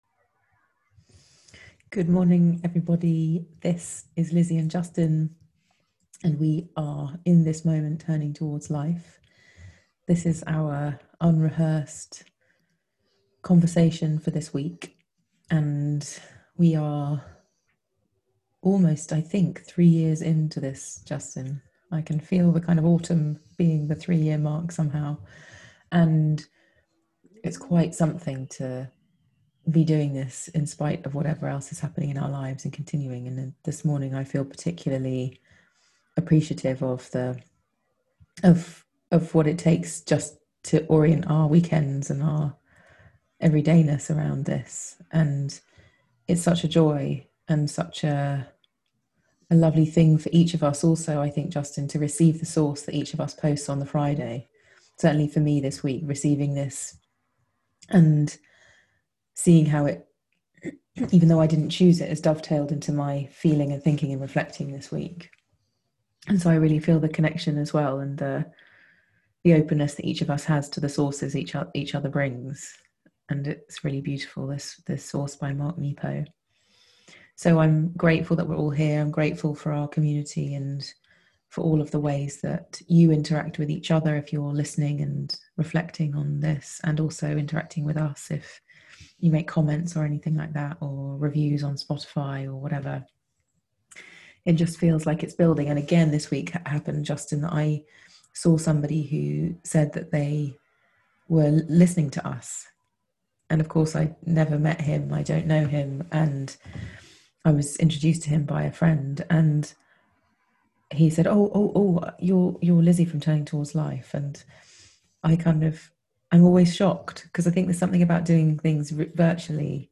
But if instead we could learn to pay attention to life, and to find a way to receive the one unique life that we are, we might find ourselves more fully inhabiting the gifts of our lives and, perhaps paradoxically, more able to be a genuine contribution to the life of others. This episode of Turning Towards Life is a conversation about how we can find our place in the beauty of things by the way we pay attention to life